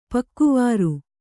♪ pakkuvāru